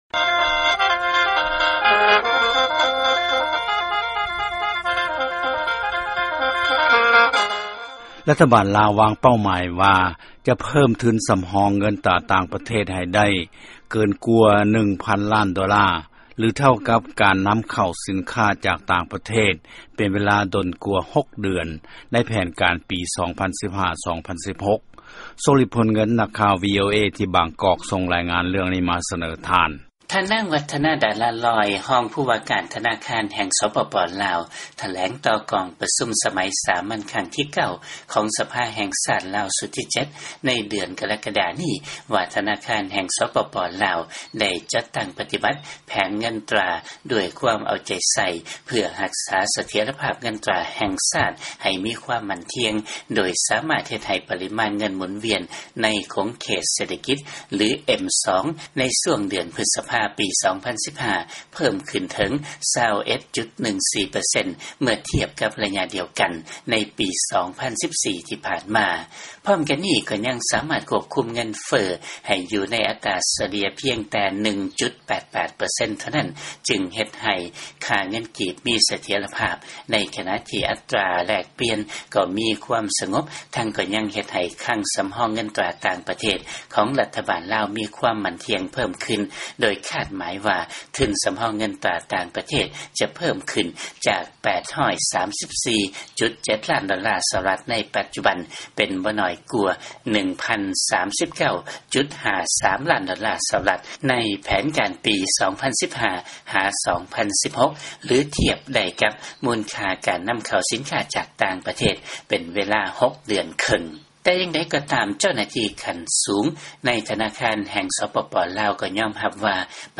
ຟັງລາຍງານ ລາວ ຈະເພີ້ມທຶນສຳຮອງ ເງິນຕາຕ່າງປະເທດ ໃຫ້ໄດ້ຫຼາຍກວ່າ 1 ຕື້ໂດລາ.